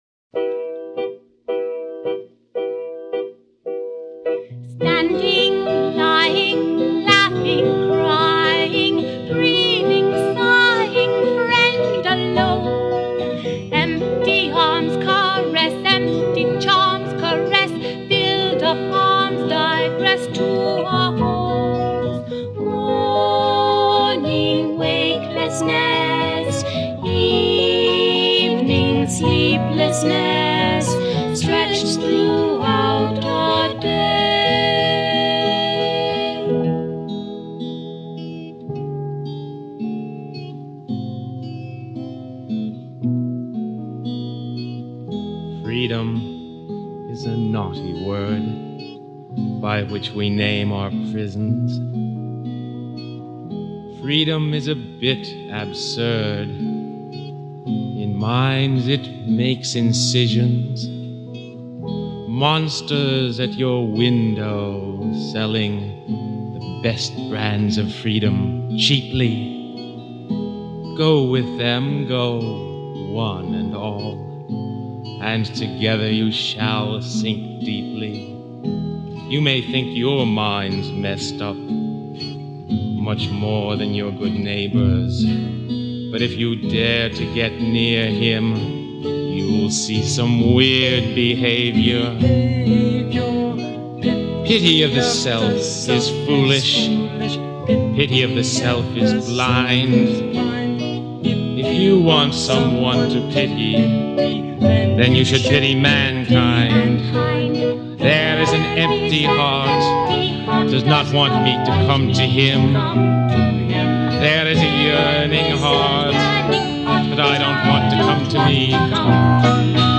piano and vocals
electric keyboards
guitar
flute
dramatic recitation on "Standing